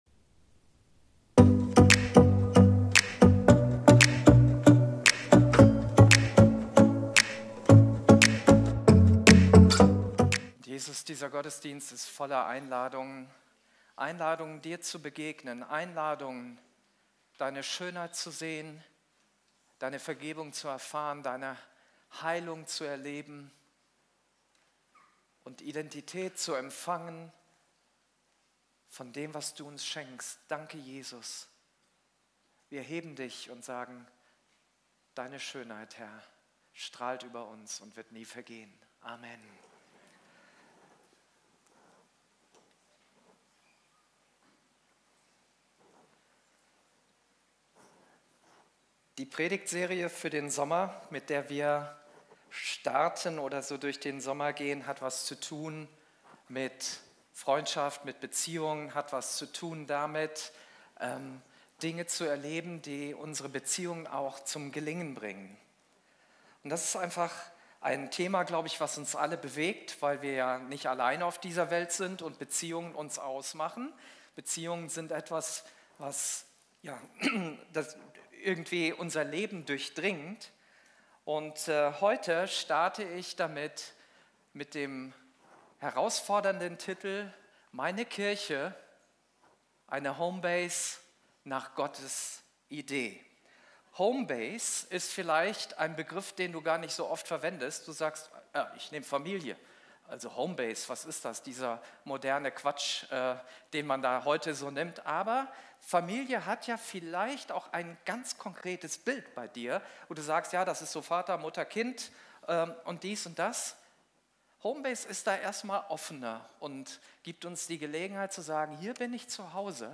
Video und MP3 Predigten
Kategorie: Sonntaggottesdienst Predigtserie: Freunde fürs Leben - Wie gute Beziehungen gelingen